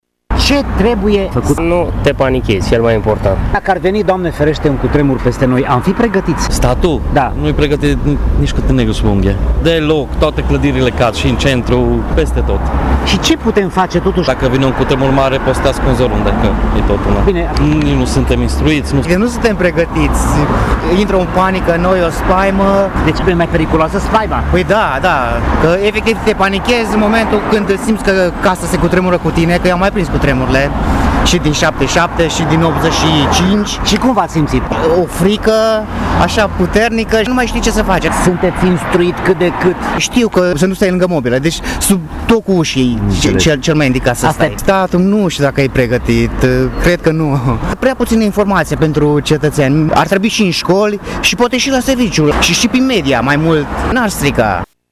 Se pune aşadar întrebarea cât de pregătite ar fi autorităţile într-o situaţie similară, însă cetățenii nu prea au încredere în aceste măsuri: